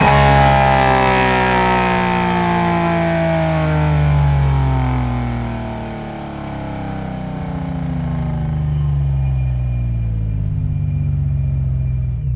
rr3-assets/files/.depot/audio/Vehicles/v6_01/v6_01_decel.wav
v6_01_decel.wav